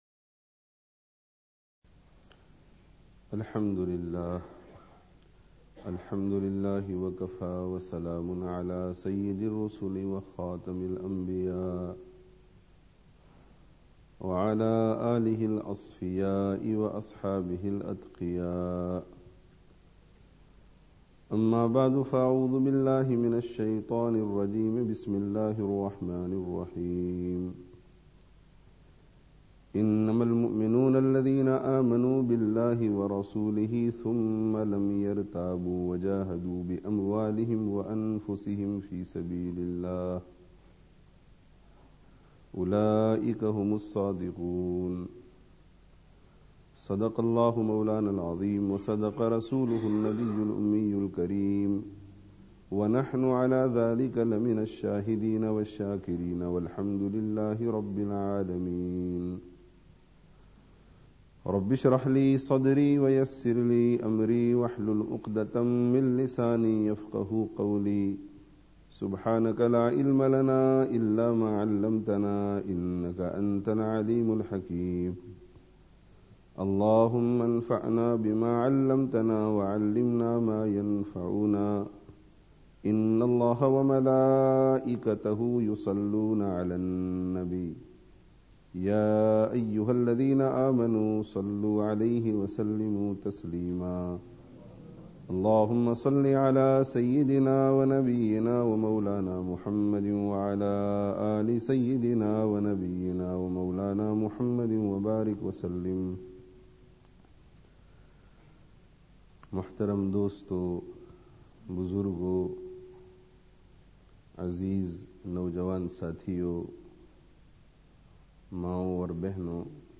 Allāh hī se Hotā hai [Jumu'ah Bayan] (Masjid An Noor, Leicester 12/05/06)